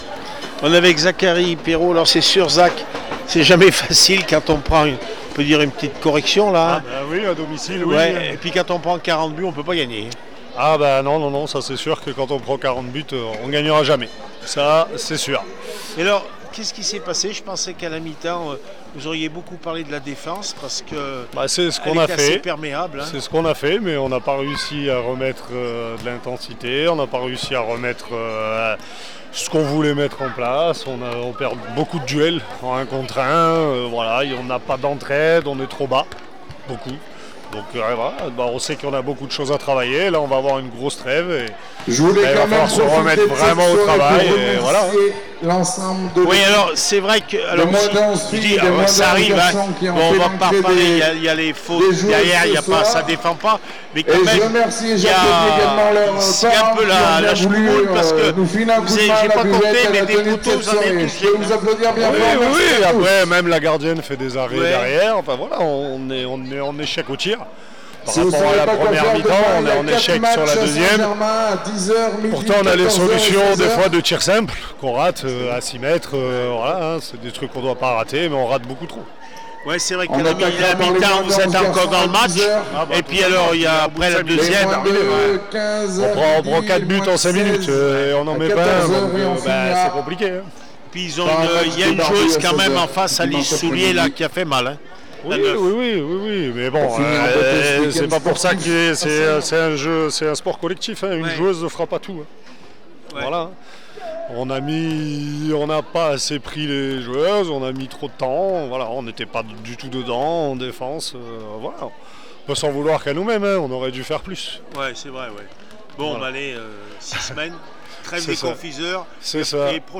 28 novembre 2024   1 - Sport, 1 - Vos interviews
hand ball n2 f st germain laprade 31- 38 st flour réaction après match